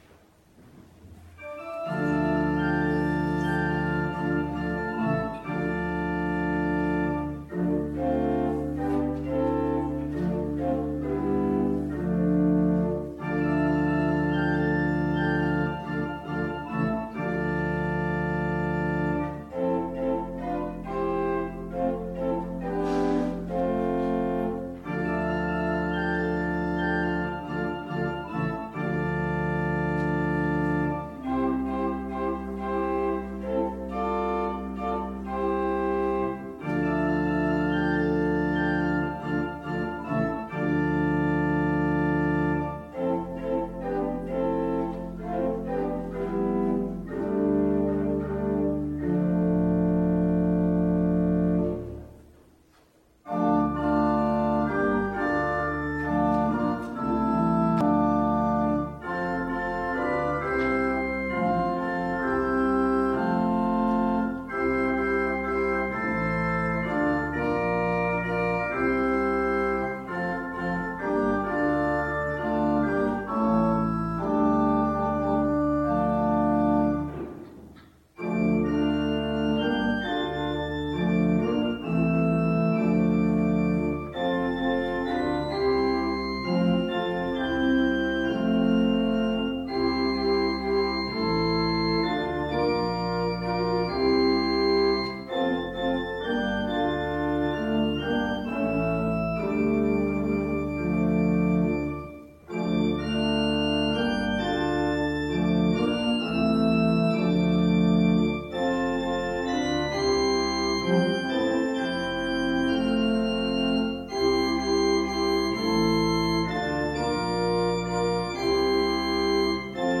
Gottesdienst vom 1. Advent nachhören
Zum Mitsingen: O Heiland, reiß die Himmel auf